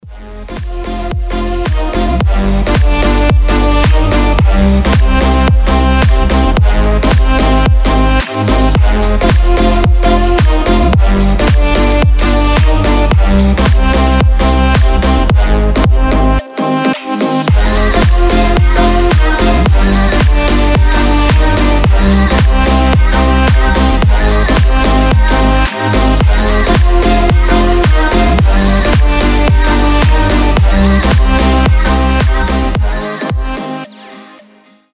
• Качество: 320, Stereo
красивые
deep house
женский голос
без слов
басы
Интересная танцевальная музыка